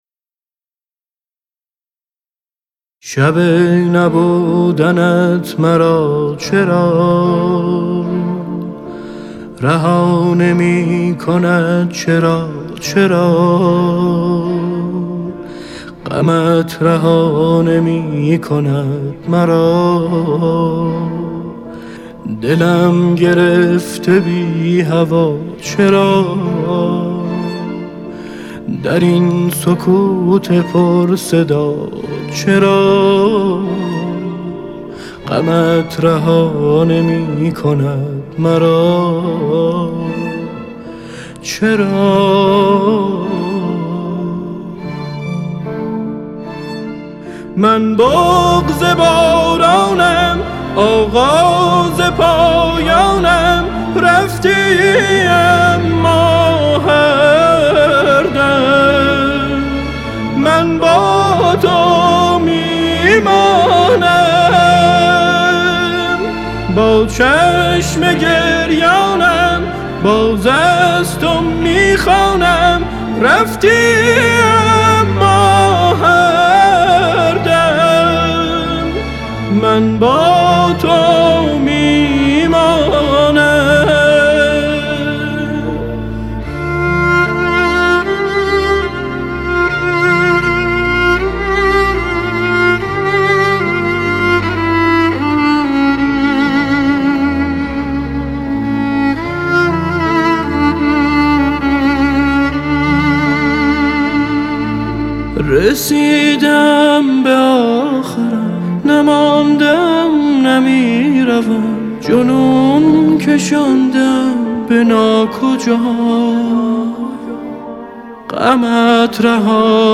ترانه تیتراژ پایانی سریال